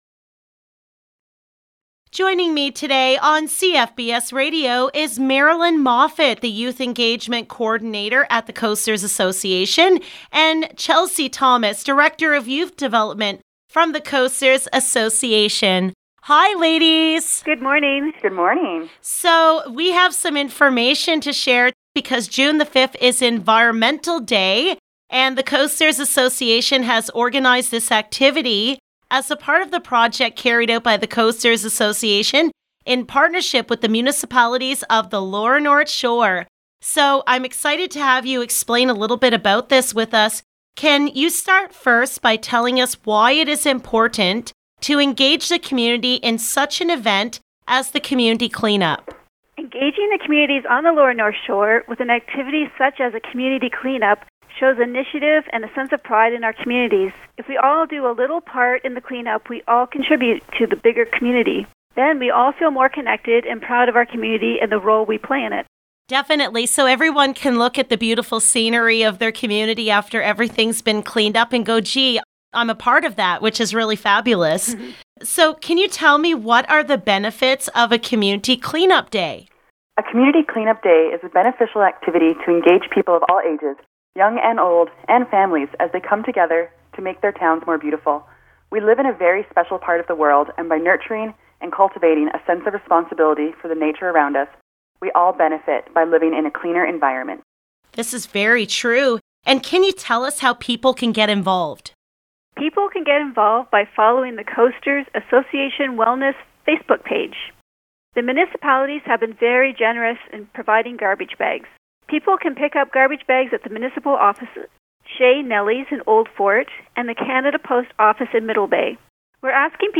LOCAL NEWS - JUNE 2, 2020 - JOIN THE FUN ON COMMUNITY CLEAN UP DAY!